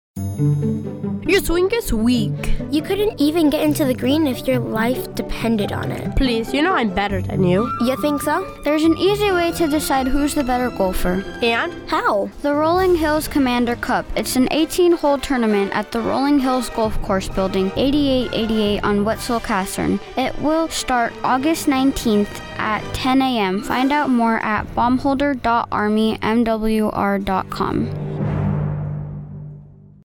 This is a 30-second radio spot promoting the Rolling Hills Commander's Cup that will take place August 29, 2025, on the Rolling Hills Golf Course in Baumholder, Germany, and will air on AFN Kaiserslautern from July 25, 2025, to Aug. 29, 2025. This 18-hole golf tournament will serve as an opportunity for members of the community to meet their commander and win an engraved trophy.